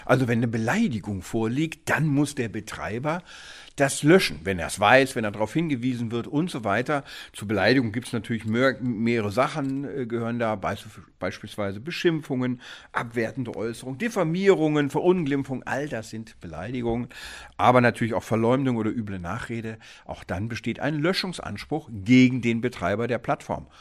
DAV, O-Töne / Radiobeiträge, Ratgeber, Recht, , , , ,